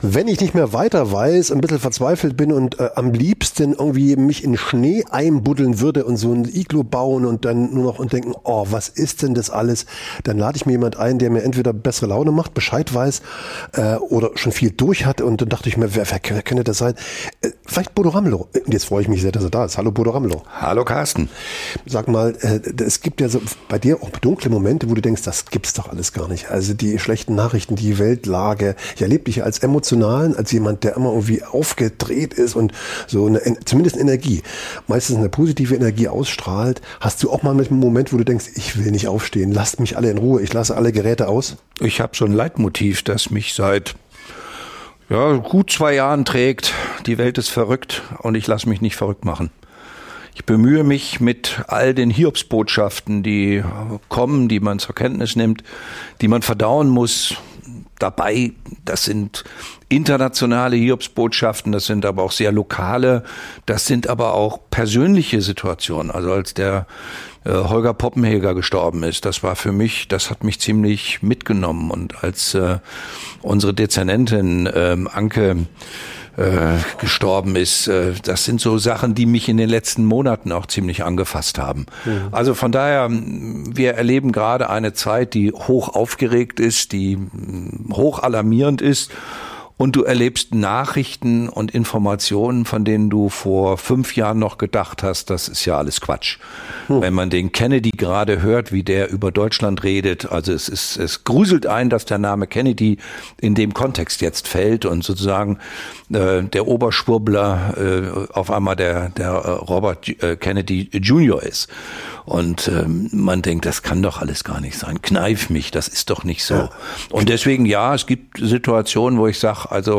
Zalando und Bischofferode stehen exemplarisch für ein System, das Profite schützt � und Würde opfert. Ein Gespräch gegen Zynismus, für Haltung.